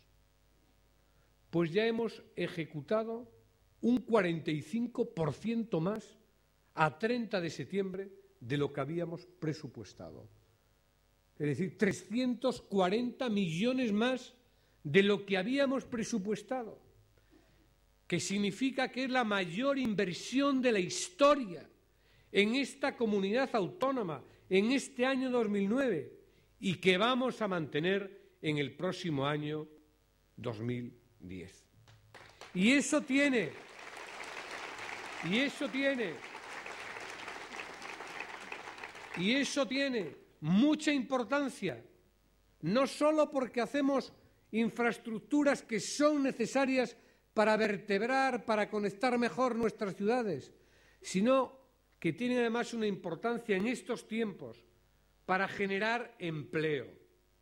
Acto Público de los socialistas de Albacete con el Vicesecretario General y ministro de Fomento